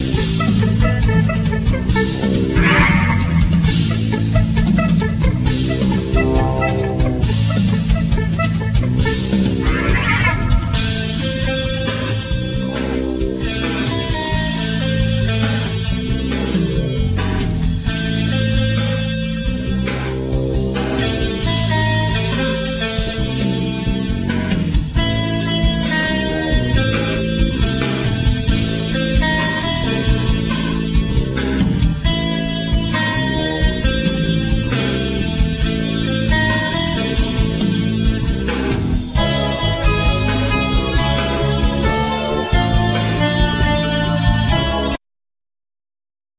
Vocals,Guitars,Drums programming,Saxophones
Drums
Bass,Opera Voice
Keyboards,Backing vocals
Sound effects,Noises